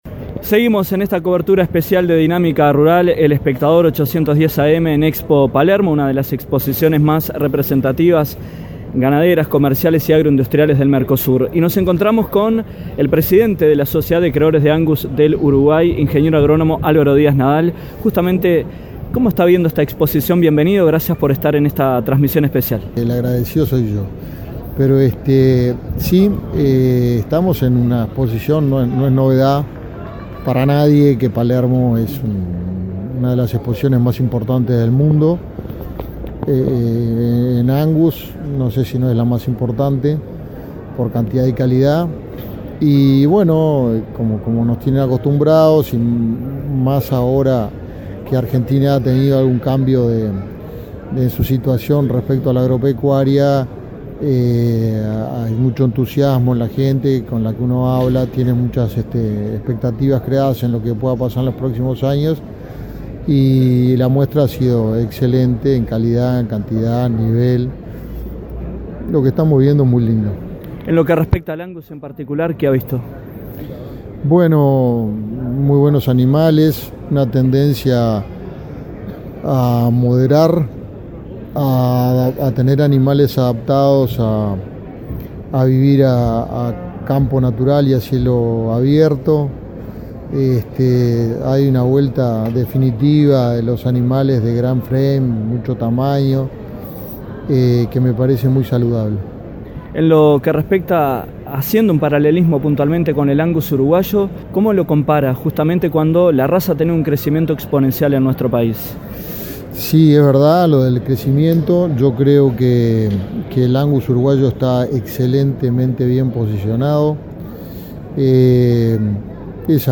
En entrevista con Dinámica Rural, el Ing.